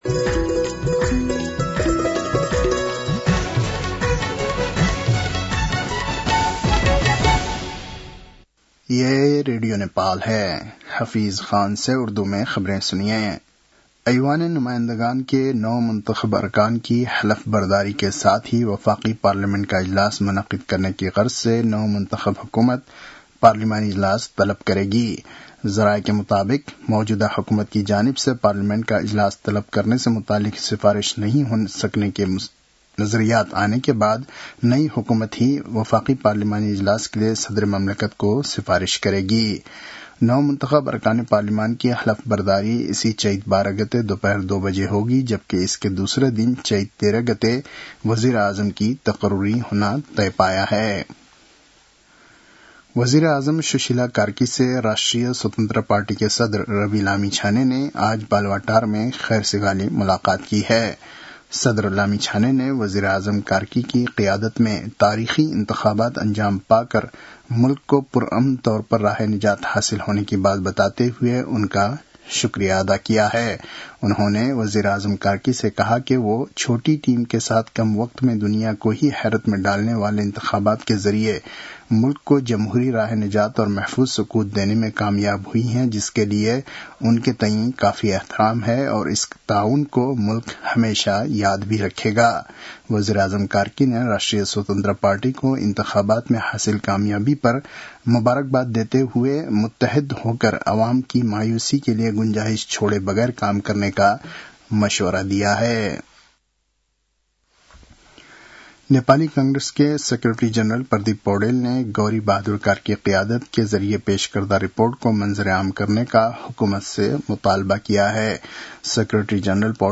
उर्दु भाषामा समाचार : ९ चैत , २०८२